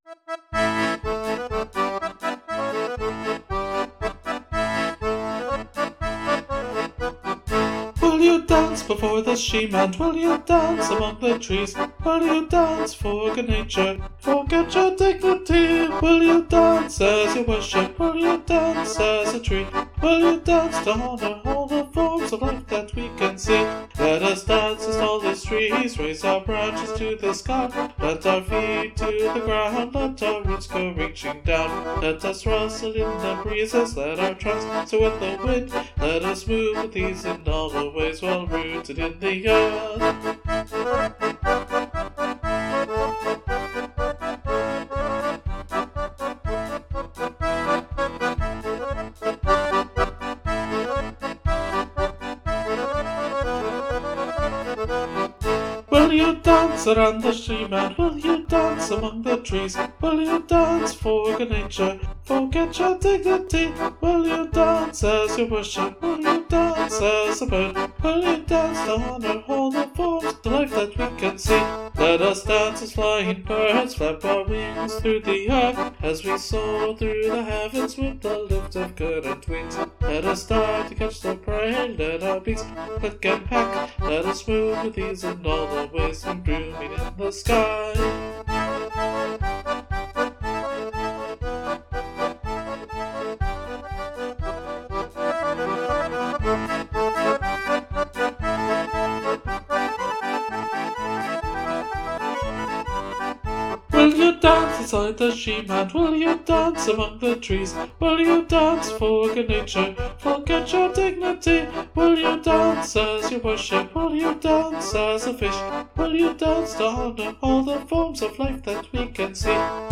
Musically, this is a pretty much straight-up polka beat. You'll notice in the recording, though, that I've been experimenting with the drum capabilities of my new-to-me electronic accordion, because it seems like adding just a hint of bass drum and hi-hat helped emphasize the rhythm a bit more clearly. The use of the mixolydian scale (with a G natural rather than a "normal" G#) gives the whole thing a bit of a folky Appalachian flavor which I quite like.